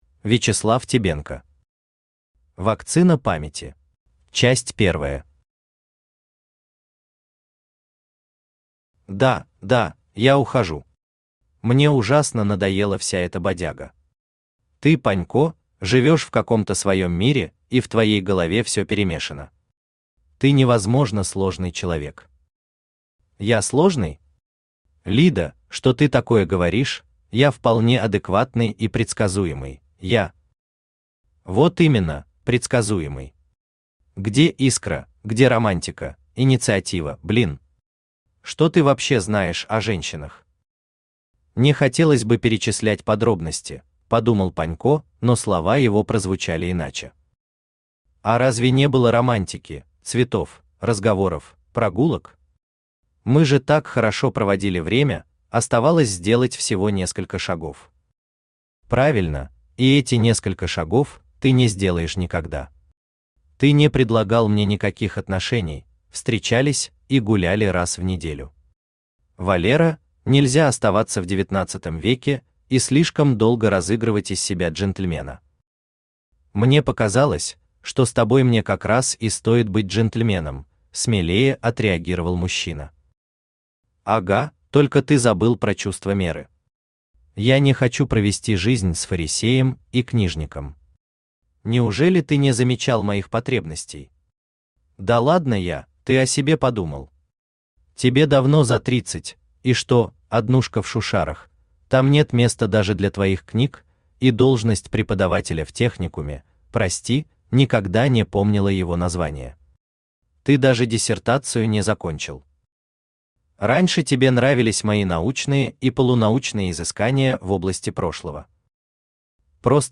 Aудиокнига Вакцина памяти Автор Вячеслав Тебенко Читает аудиокнигу Авточтец ЛитРес.